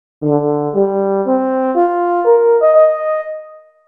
Here are two well-known examples: Alexander Scriabin’s so-called ‘mystic chord’ and the horn call (a pile of fourths) that opens Schönberg’s Chamber Symphony No. 1: